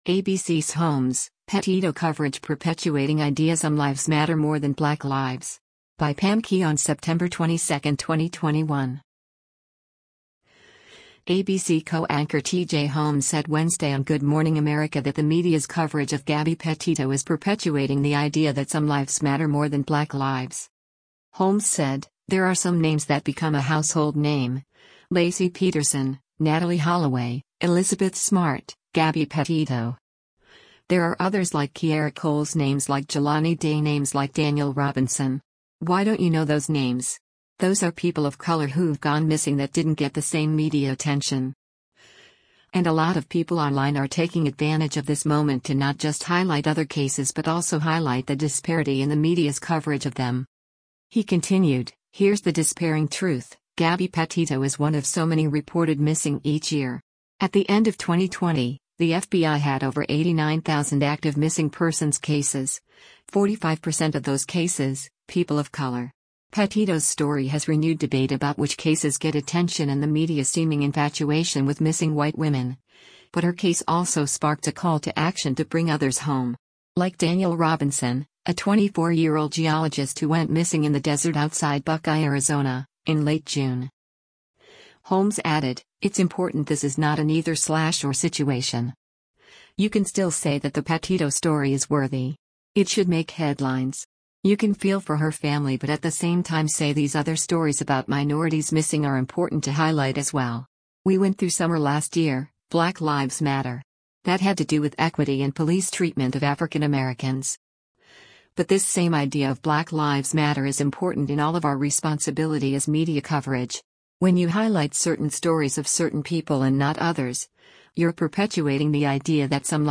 ABC co-anchor T.J. Holmes said Wednesday on “Good Morning America” that the media’s coverage of Gabby Petito is “perpetuating the idea that some lives matter more” than Black lives.